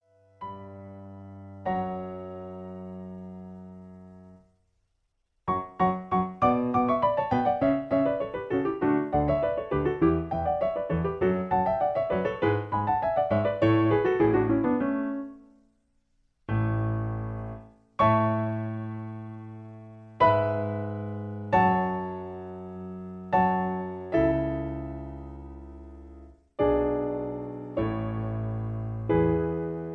Original Key (D) Piano Accompaniment